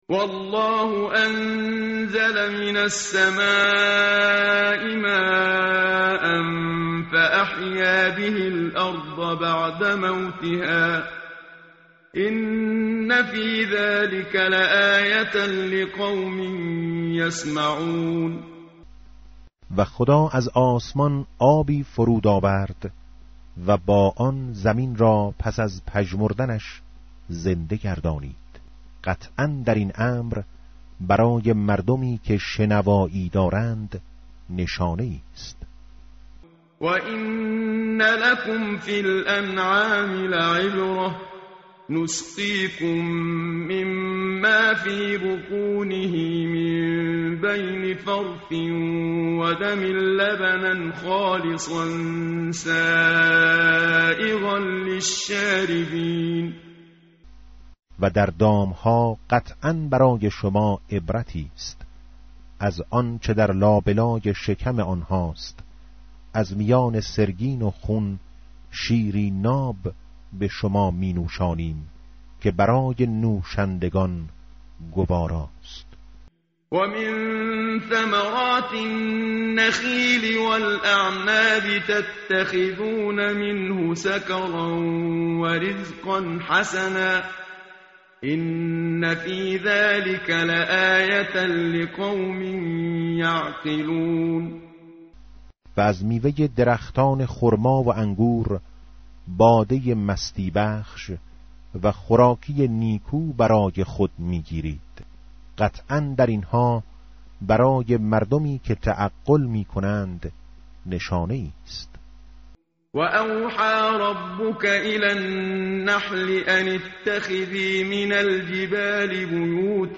tartil_menshavi va tarjome_Page_274.mp3